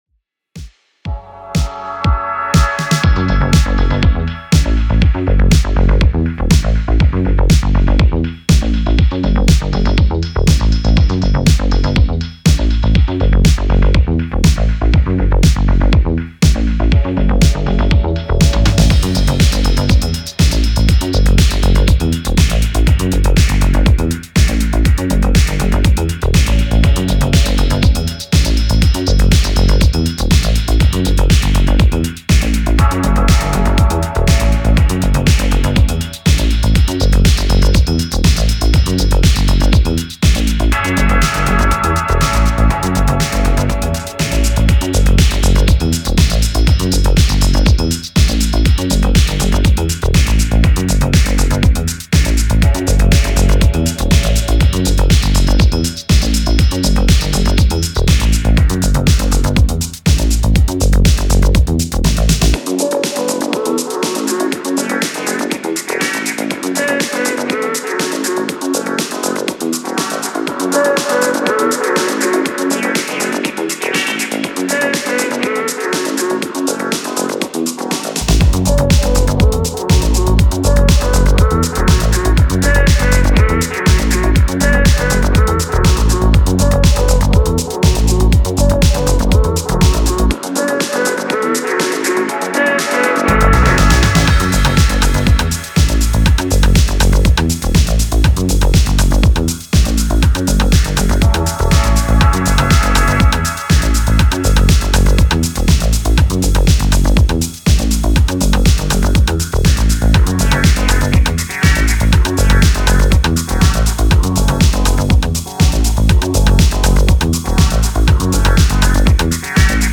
80sイタロ・ディスコのようなシンセベースを携えスペース・アウトする